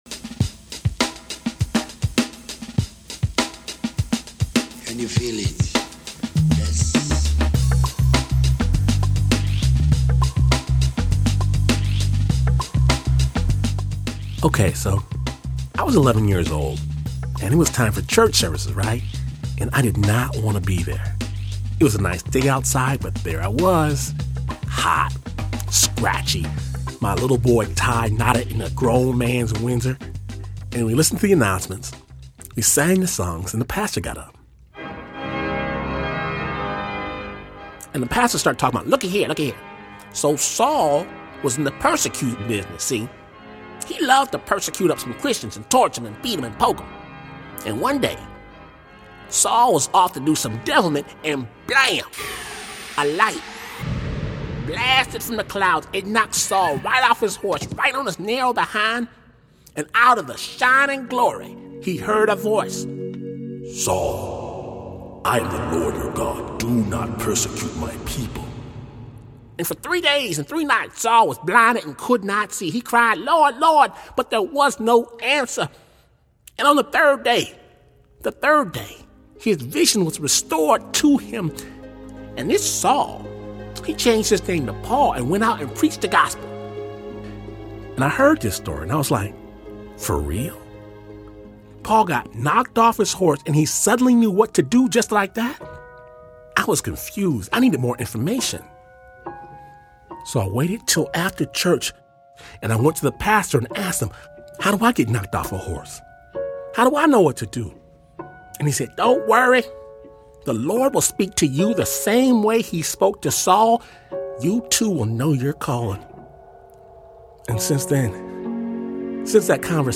Snap Judgment (Storytelling, with a BEAT) mixes real stories with killer beats to produce cinematic, dramatic, kick-ass radio. Snap’s raw, musical brand of storytelling dares listeners to see the world through the eyes of another.